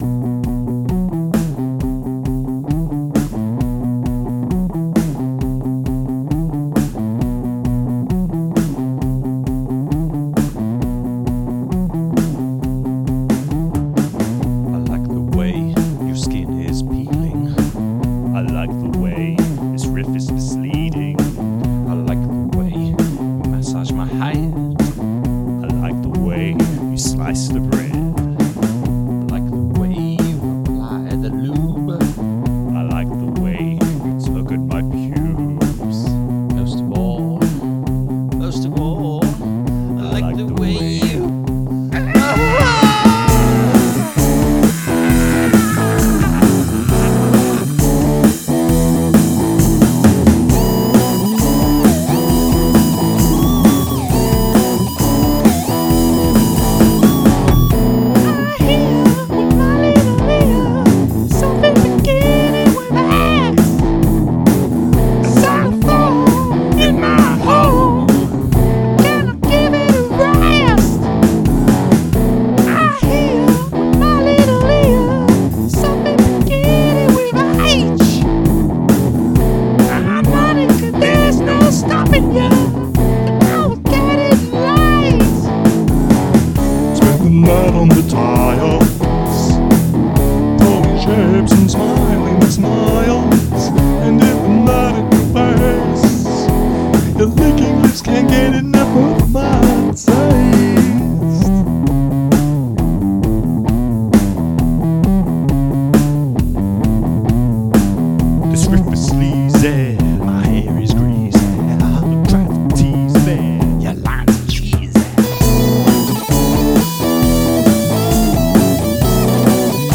a volley of screaming shredded notes